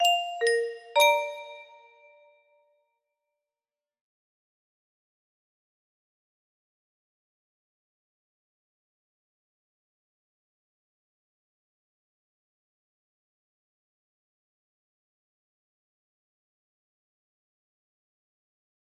Doorbell 1 music box melody